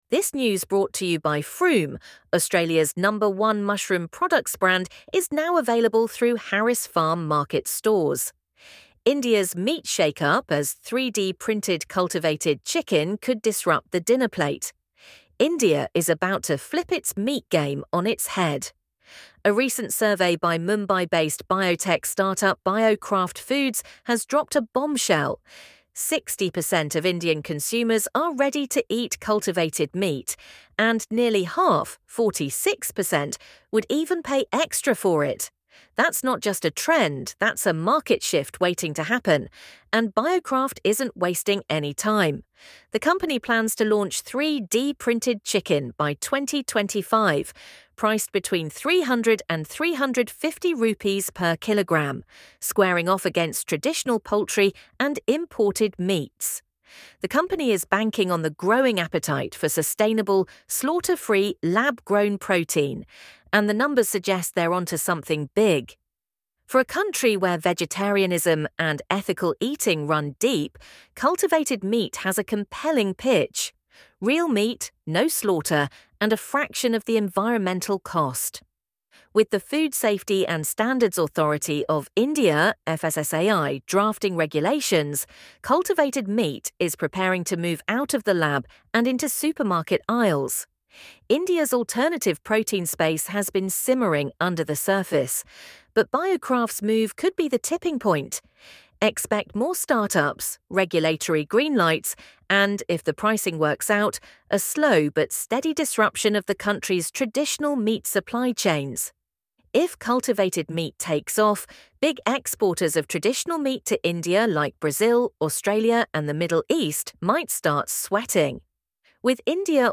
LISTEN TO THIS AUDIO ARTICLE India is about to flip its meat game on its head.